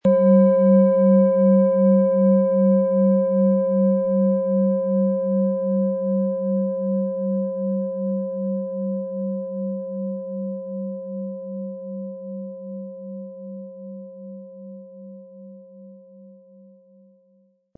Tageston
Die Planetenklangschale Tageston ist handgefertigt aus Bronze.
MaterialBronze